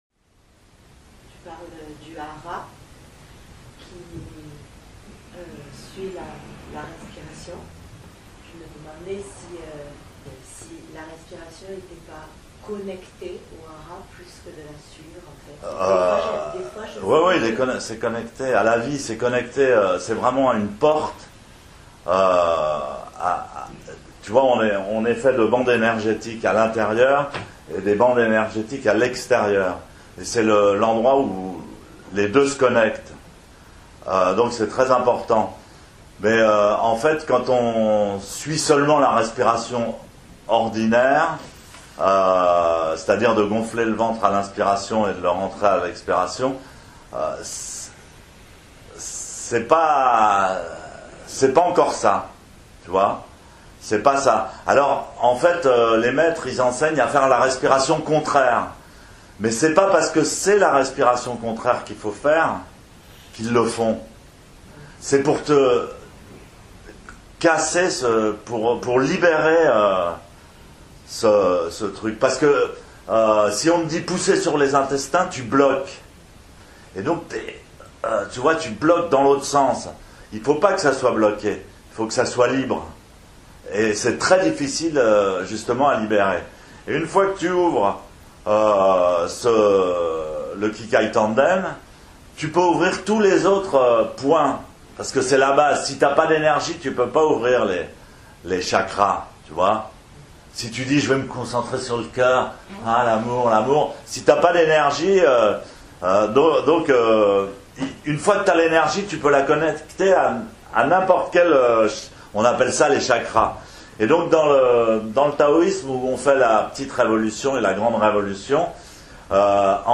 Mondo - questions à un maître zen